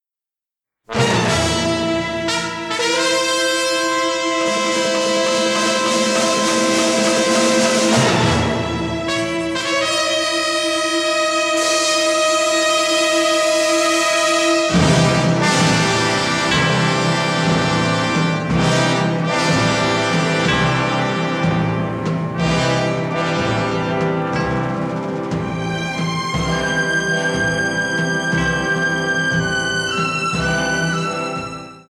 jazz-rooted score